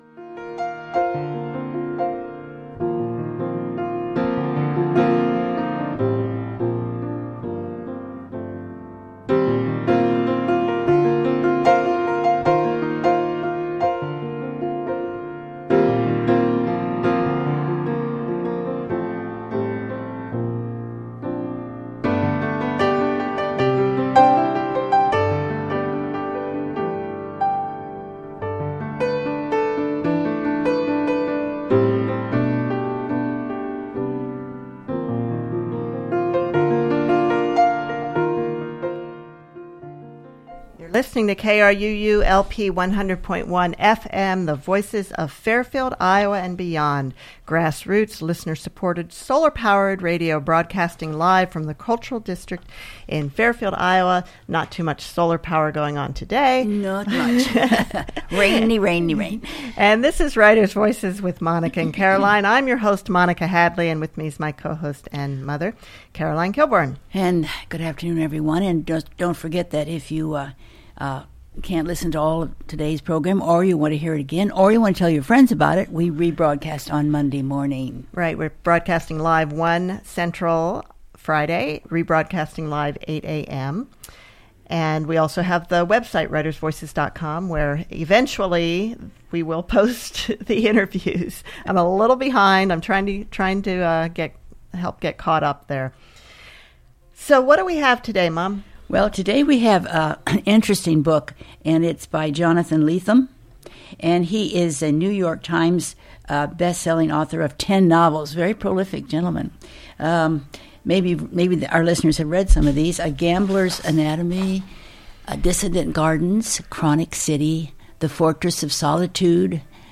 Jonathan Lethem joins Writers Voices for a conversation on writing and his new book of essays – More Alive and Less Lonely.